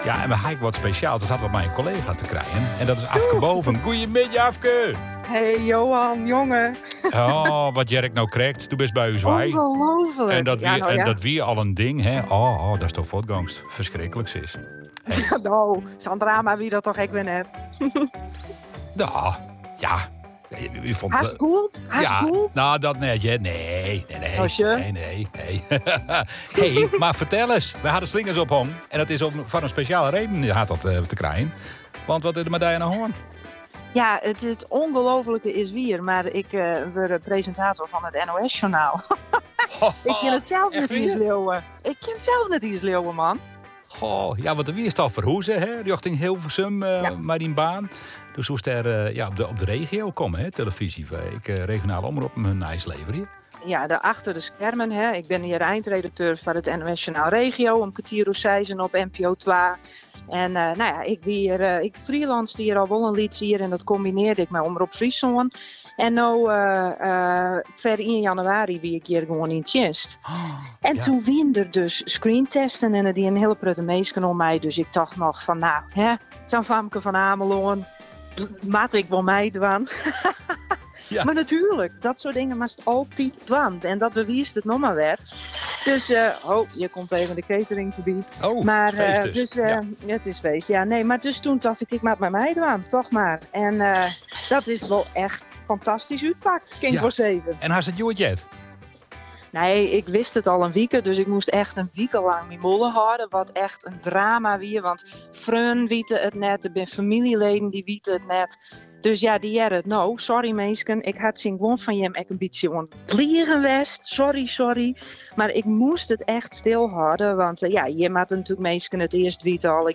gesprek